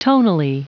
Prononciation du mot tonally en anglais (fichier audio)
Prononciation du mot : tonally